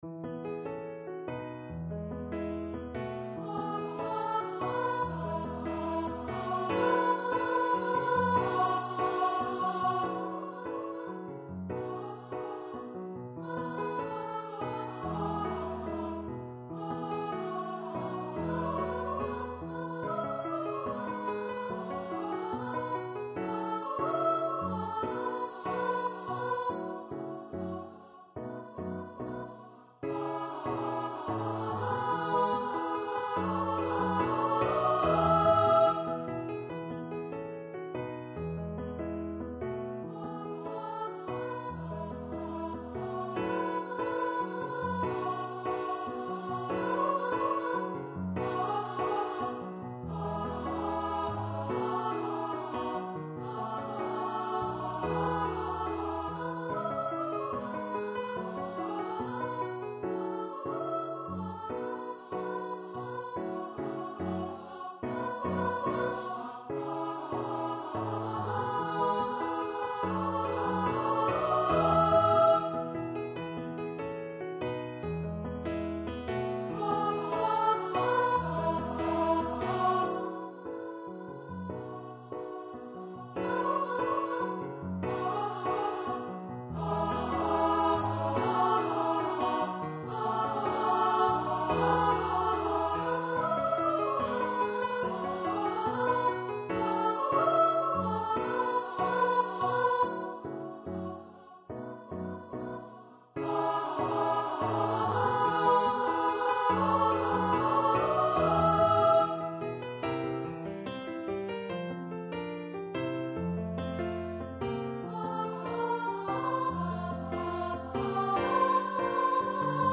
for piano and upper voice choir
Choir - 2 part upper voices